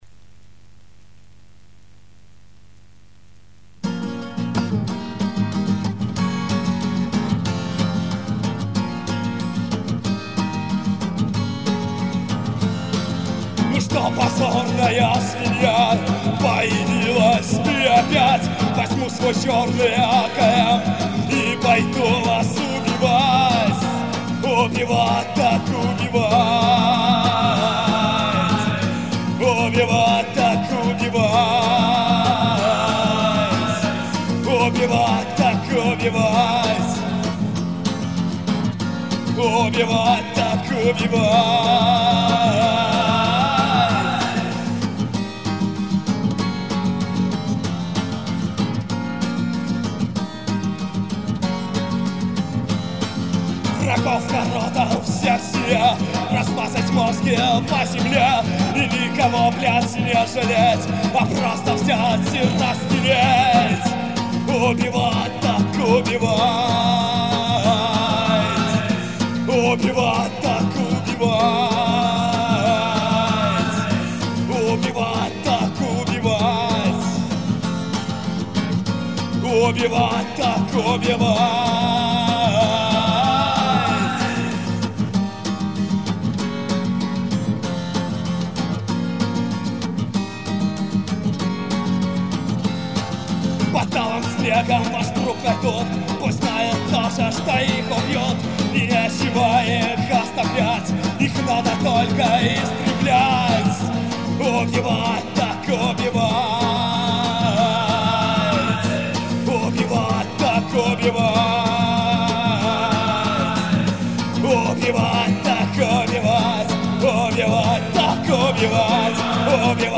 Записано на квартире.